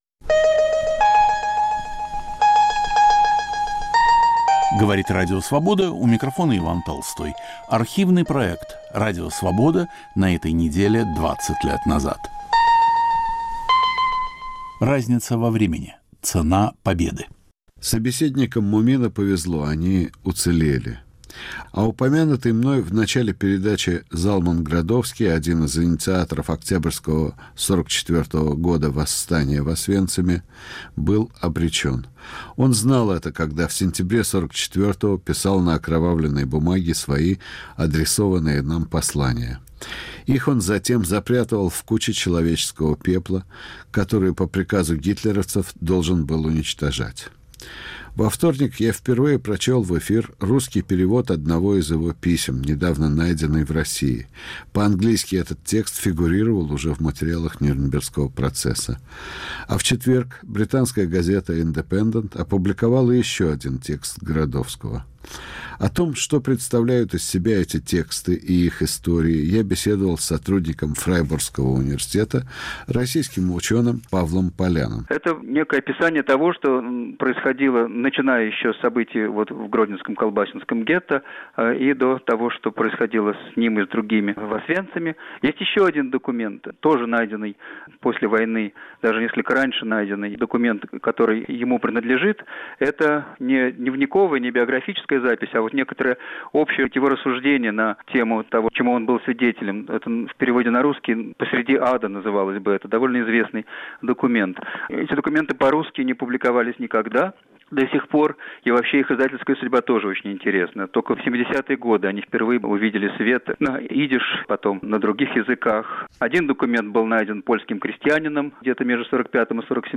Рассказывают выжившие заключенные.